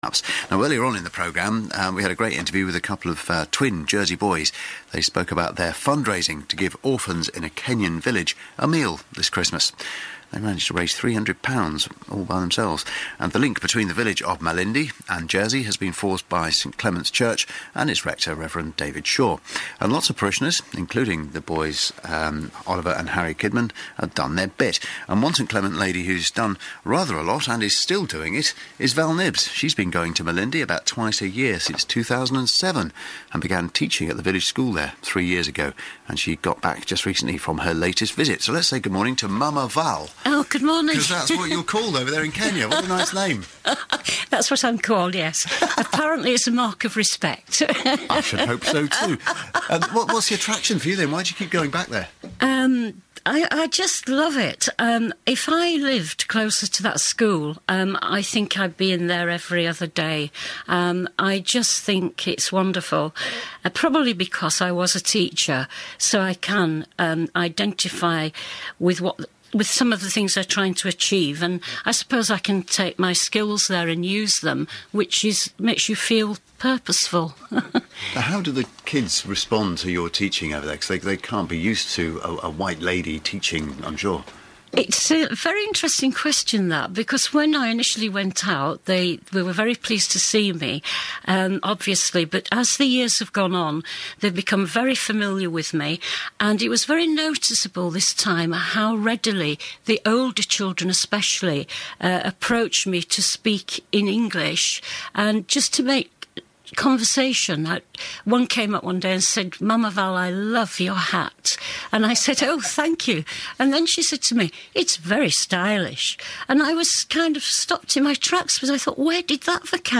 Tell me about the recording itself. on BBC Radio Jersey